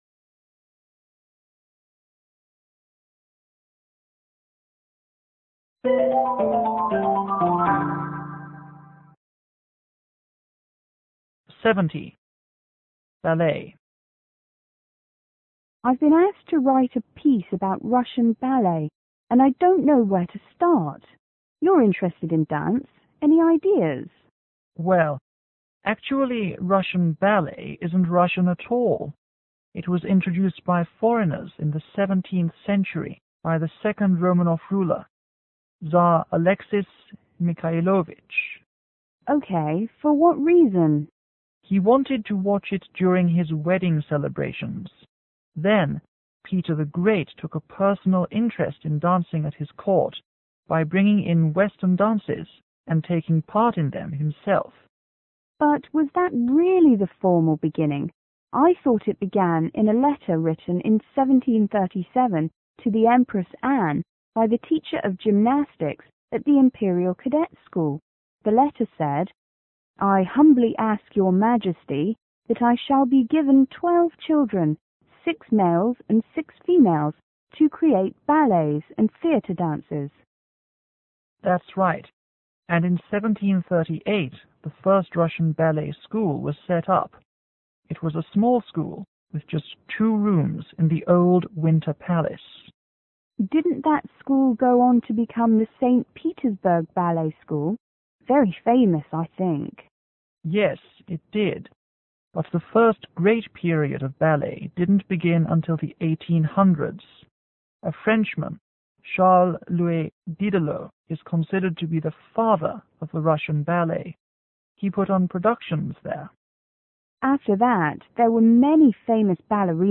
FW: Female writer    MW: Male writer